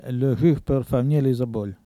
Il crie pour attirer l'essaim d'abeilles
Langue Maraîchin
Catégorie Locution